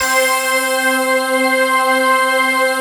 Index of /90_sSampleCDs/Best Service Dream Experience/SYN-PAD